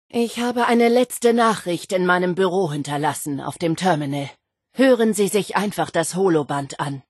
Fallout 76: Audiodialoge